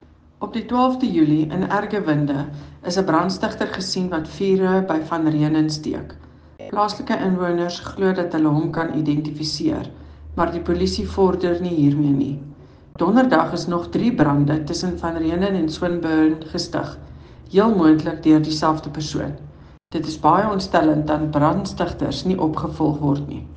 Afrikaans by Cllr Eleanor Quinta and